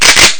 RELOAD.mp3